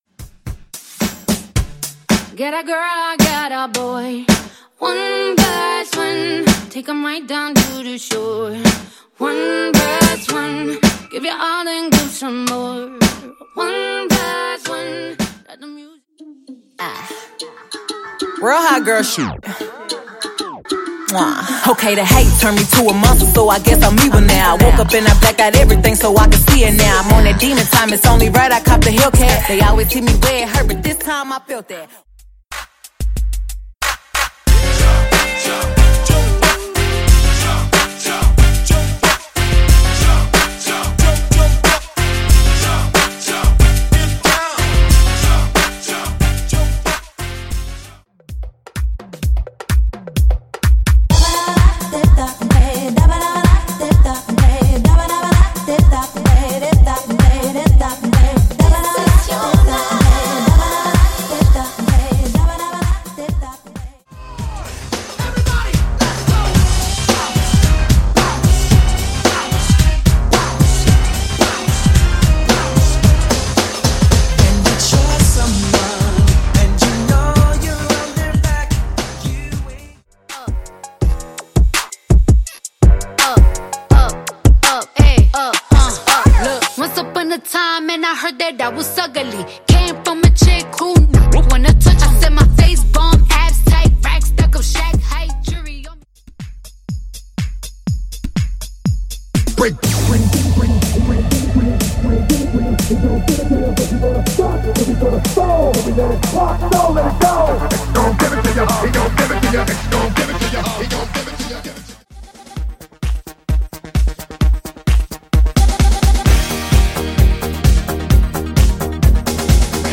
Genre: MOOMBAHTON
Clean BPM: 96 Time